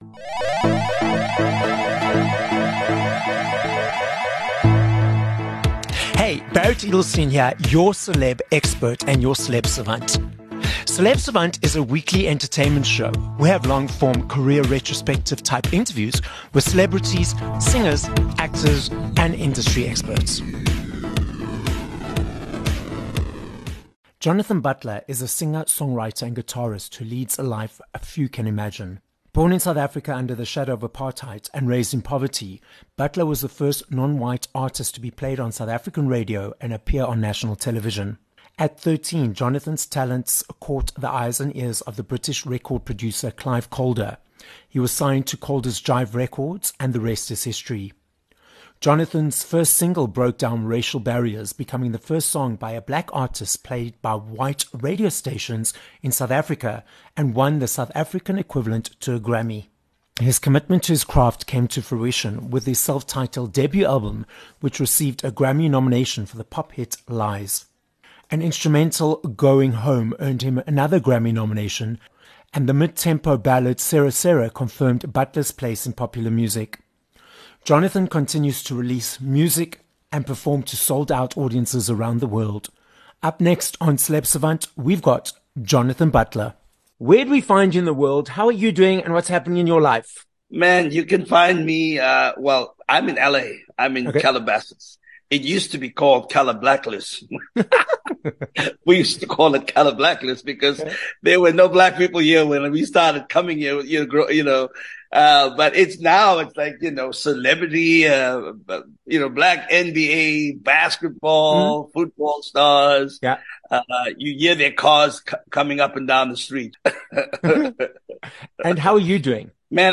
2 Jul Interview with Jonathan Butler